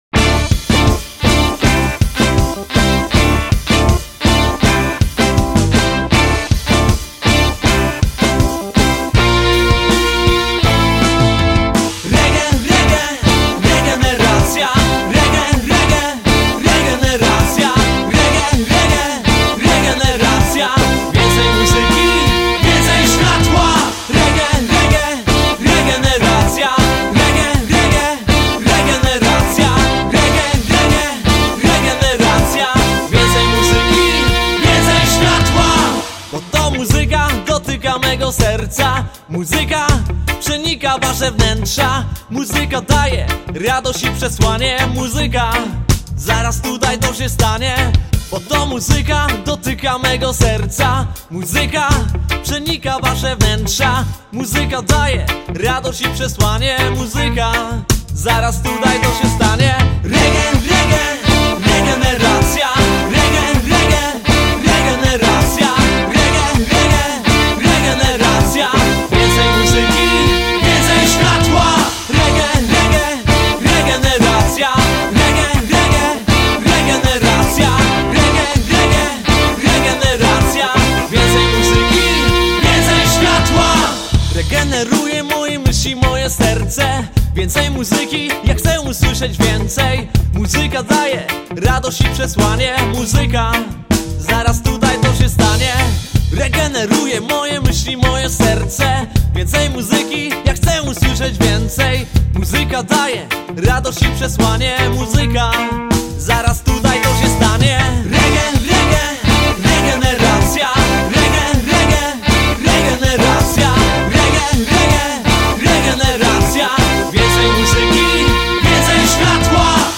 klawisze
bębny
trąbka
sax
gitara